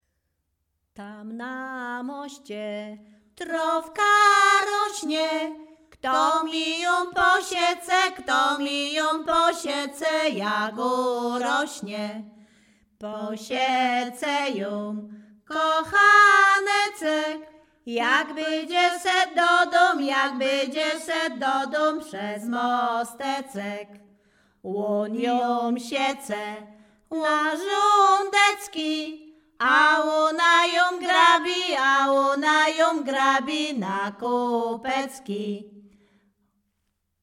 Śpiewaczki z Chojnego
województwo łódzkie, powiat sieradzki, gmina Sieradz, wieś Chojne
liryczne miłosne kosiarskie